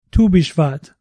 How do you pronounce Tu Bishvat?
tu-bishvat.mp3